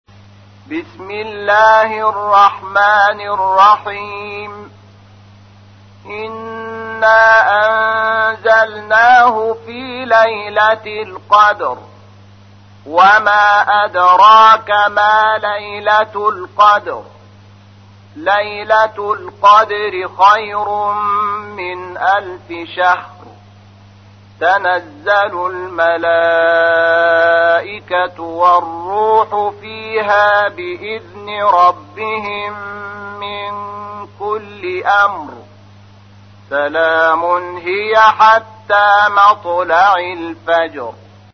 تحميل : 97. سورة القدر / القارئ شحات محمد انور / القرآن الكريم / موقع يا حسين